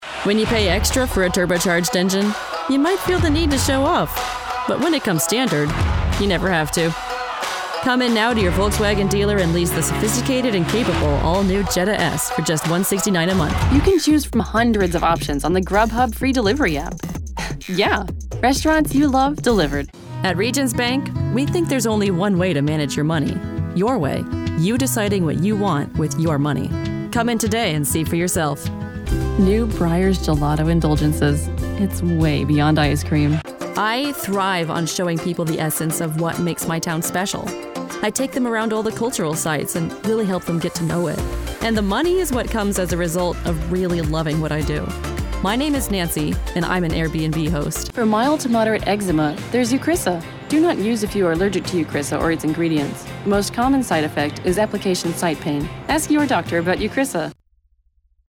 Savvy millennial voice actress for games, commercials, explainers, and more
Commercial
Young Adult